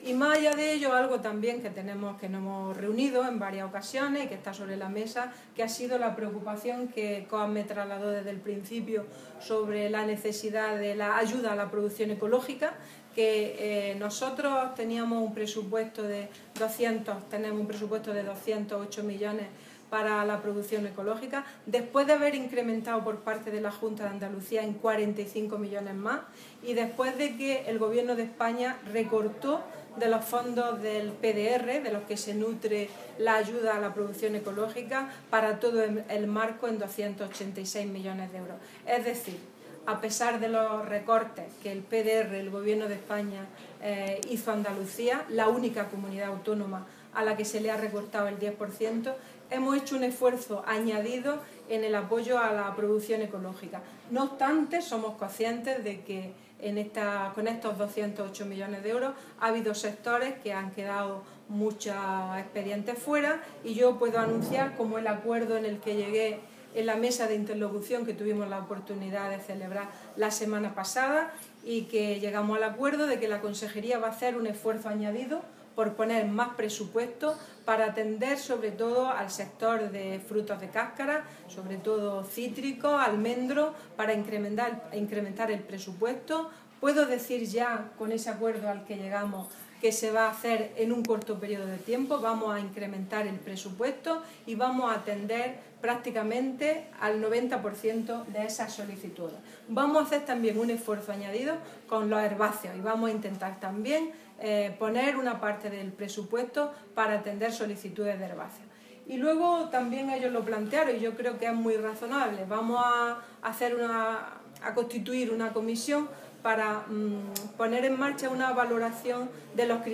Declaraciones consejera sobre ayudas producción ecológica